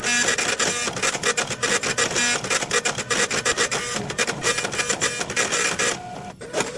爱普生M188B打印机 " 爱普生收据打印机4
描述：这台epson m188b打印机位于曼彻斯特国际机场3号航站楼的一家商店。它打印出收据。